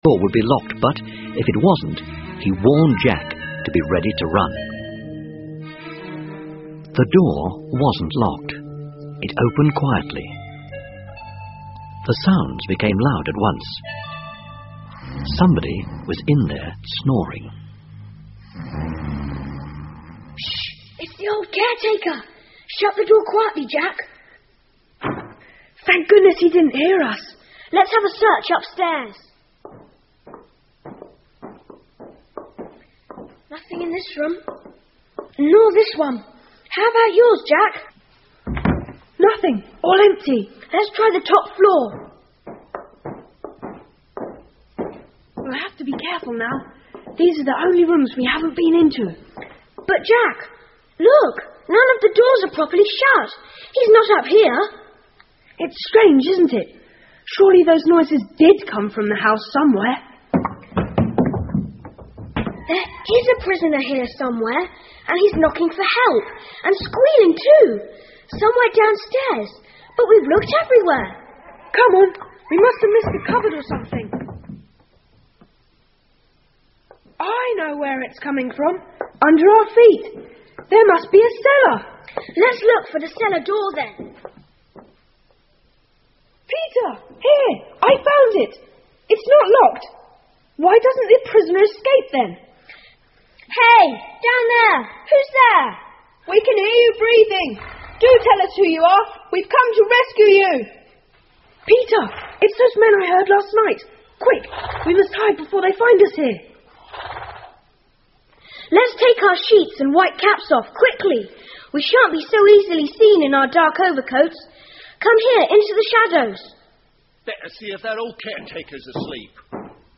The Secret Seven 儿童英文广播剧 6 听力文件下载—在线英语听力室